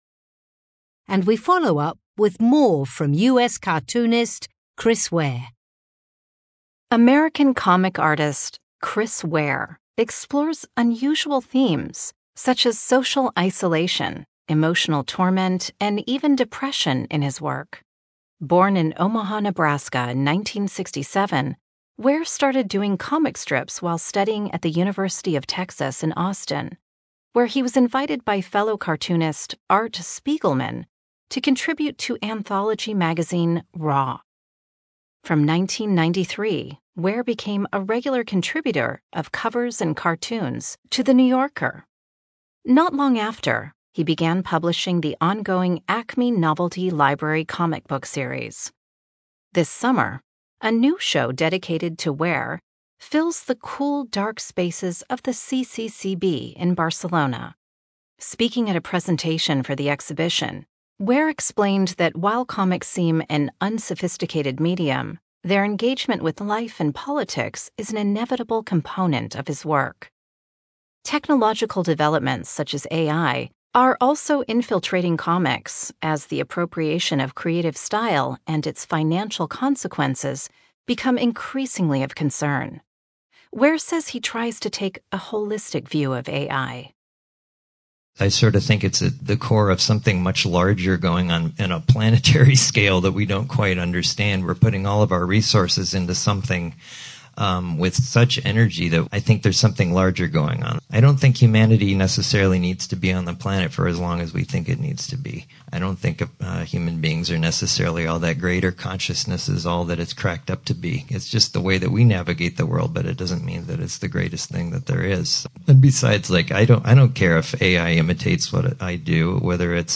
Speaker (American accent)